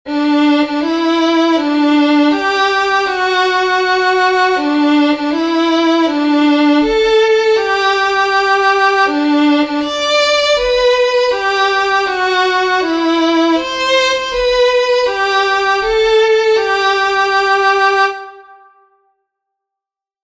Metro: 3/4
spartiti violino